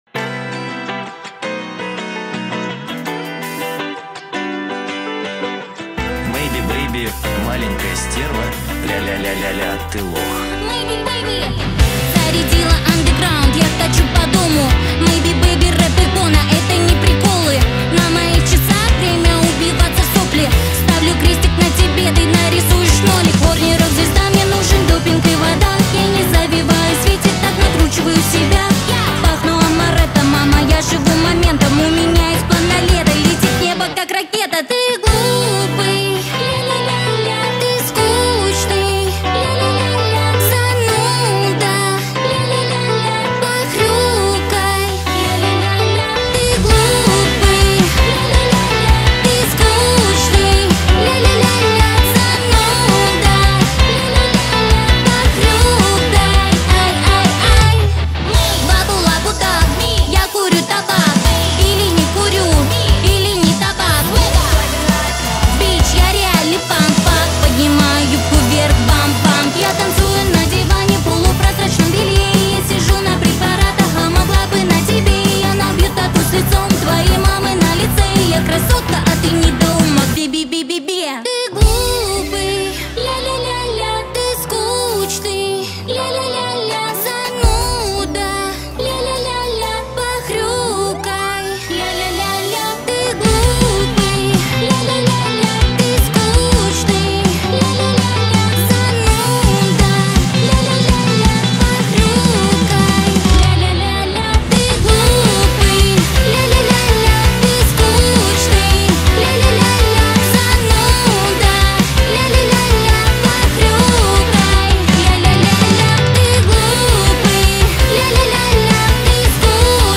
акустическая версия